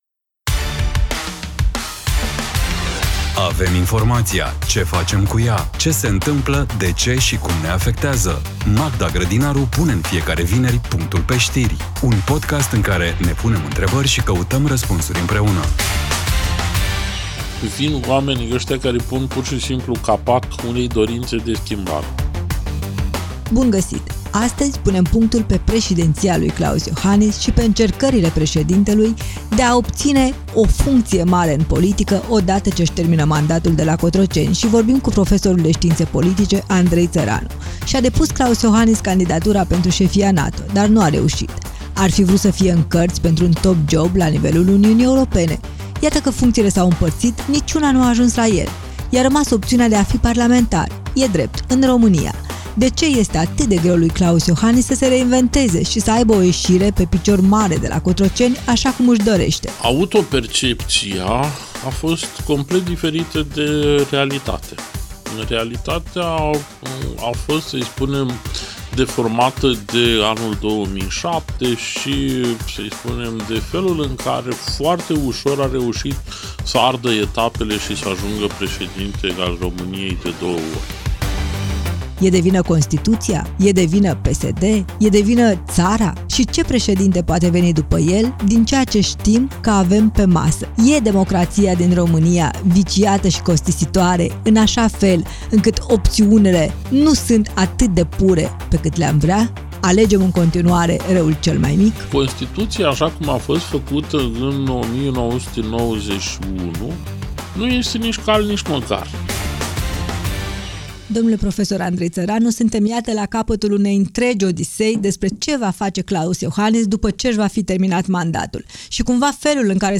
Un dialog inteligent, relaxat și necesar.